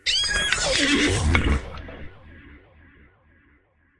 PixelPerfectionCE/assets/minecraft/sounds/mob/horse/skeleton/idle3.ogg at c12b93b9c6835a529eb8ad52c47c94bf740433b9